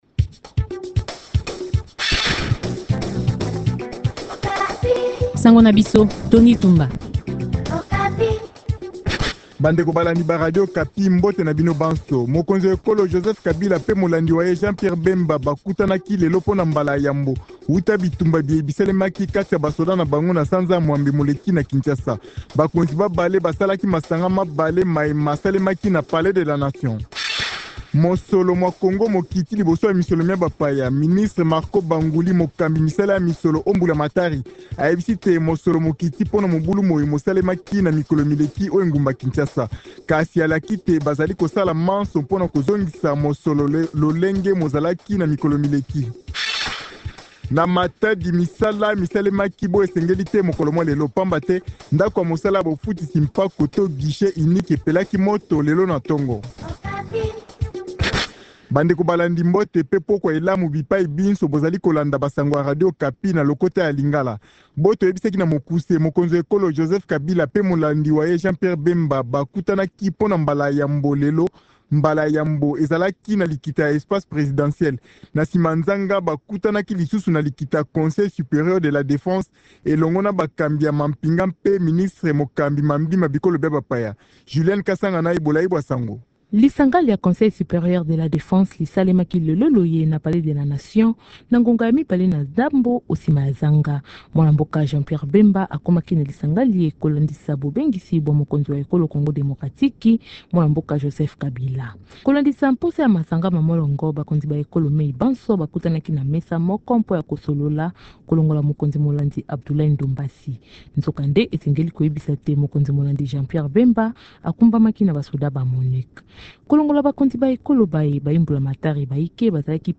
Journal Lingala